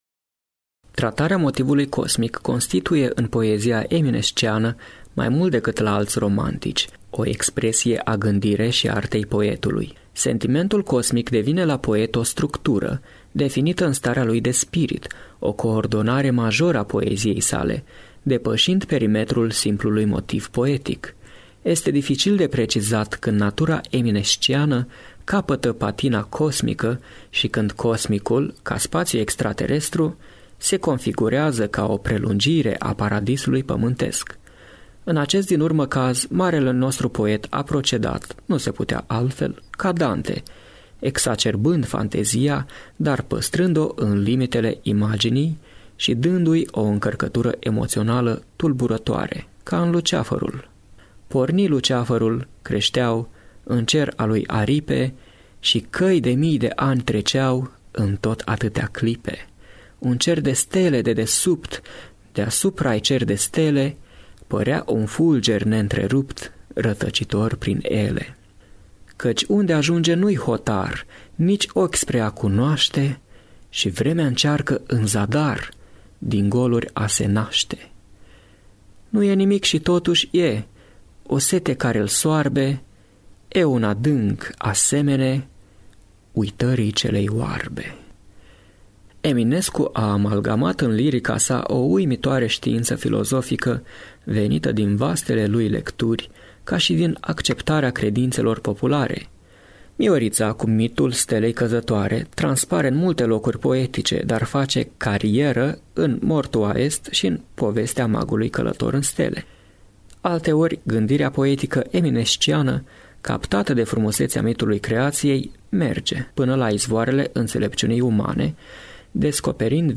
“A iubi stelele” și-a intitulat el, comentariul cu care deschidem această ediție și pe care îl atașăm și aici, pentru a putea fi ascultat on-line…